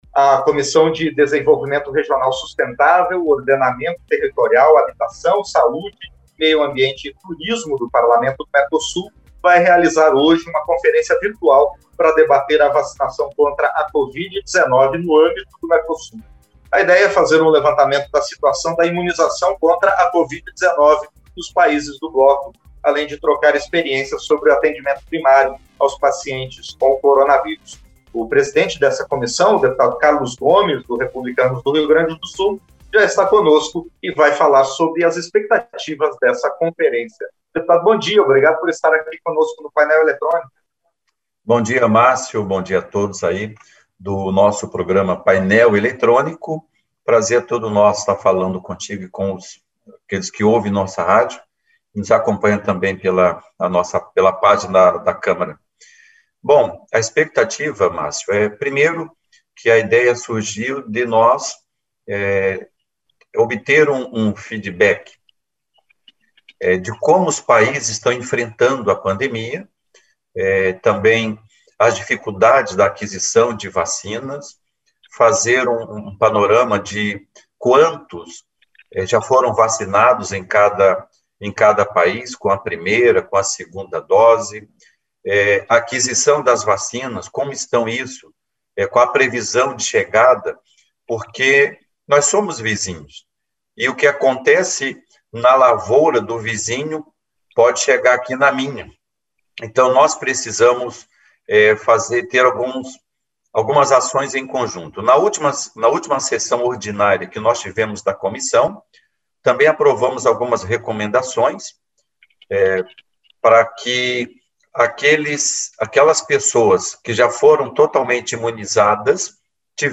Entrevista - Dep. Carlos Gomes (REP-RS)